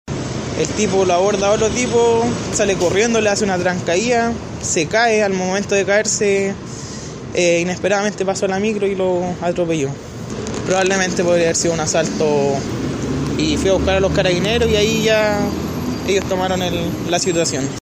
Un testigo relató lo que vio en el sitio del suceso.